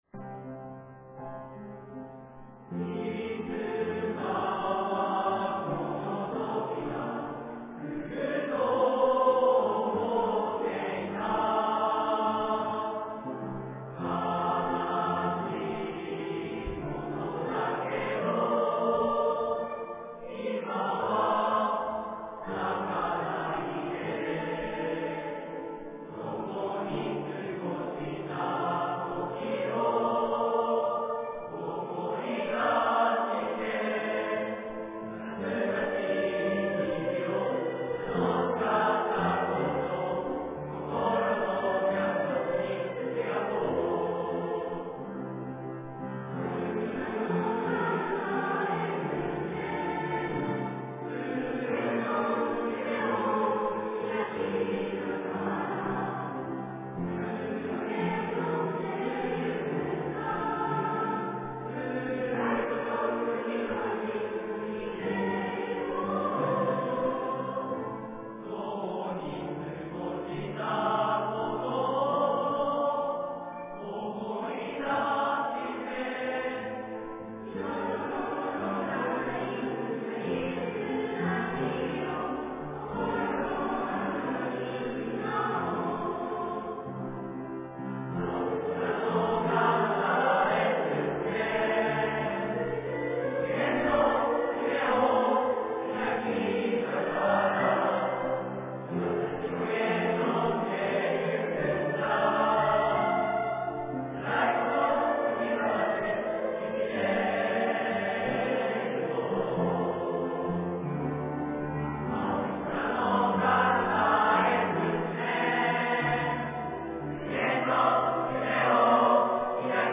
zenkogassho.mp3